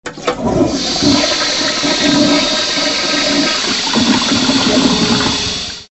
Звуки унитаза
Шум смыва унитаза